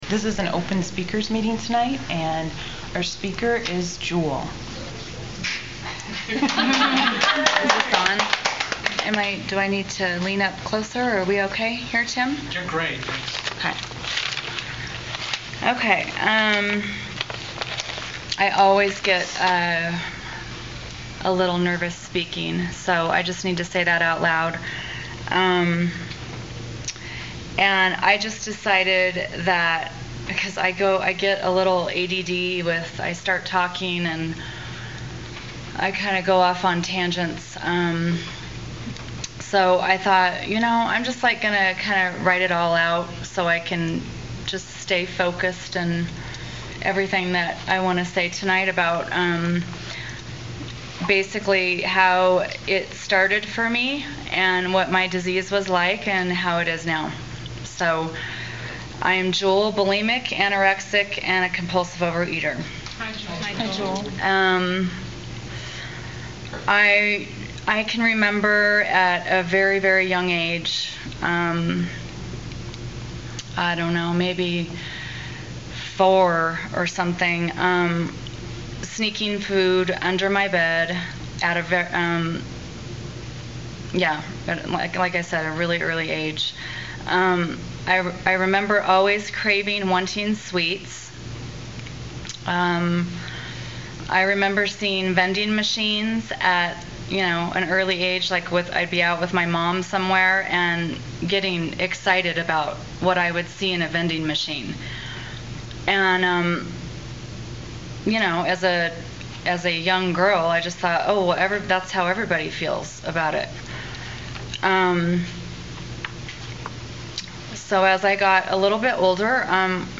Speakers Meeting